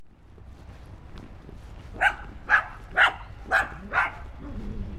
Index of /cianscape/birddataDeverinetal2025/File_origin/Noise-ESC-50/dog